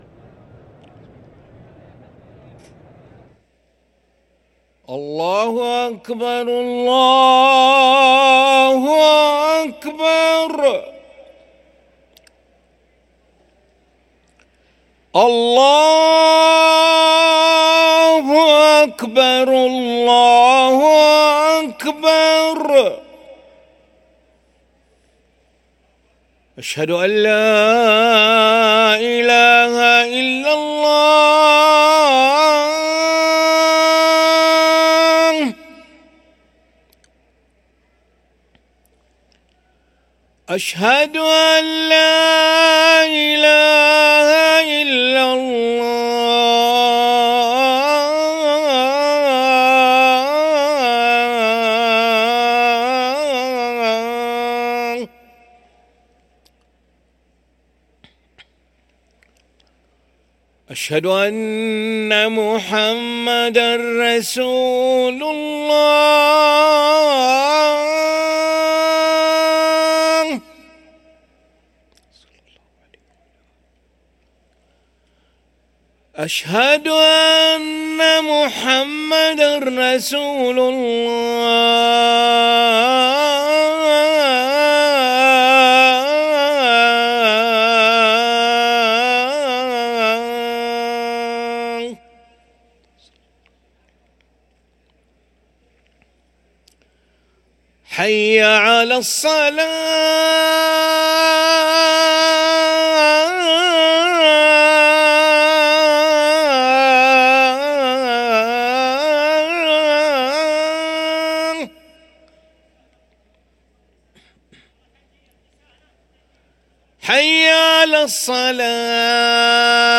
أذان العشاء للمؤذن علي ملا الأحد 12 جمادى الأولى 1445هـ > ١٤٤٥ 🕋 > ركن الأذان 🕋 > المزيد - تلاوات الحرمين